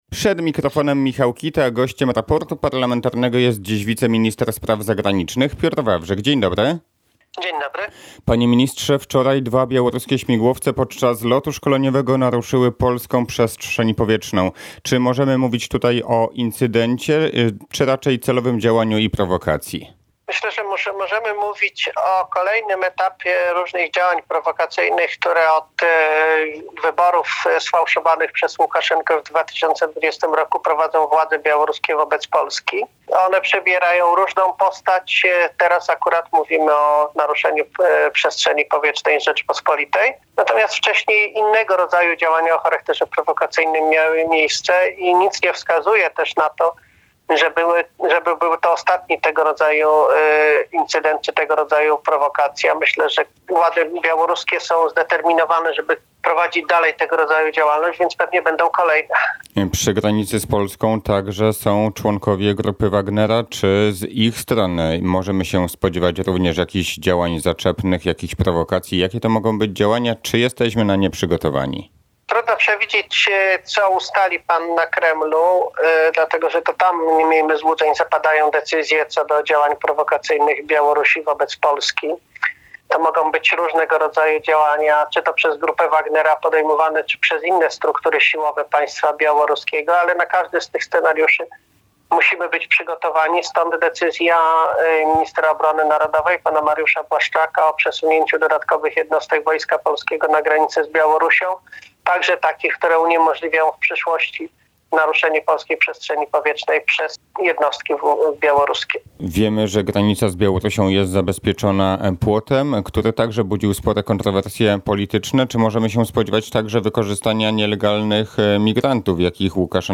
– Naruszenie polskiej przestrzeni powietrznej przez białoruskie śmigłowce to kolejny etap działań prowokacyjnych, które od sfałszowanych w 2020 roku przez Łukaszenkę wyborów, prowadzą władze białoruskie wobec Polski – stwierdził Piotr Wawrzyk, wiceminister spraw zagranicznych w Raporcie Parlamentarn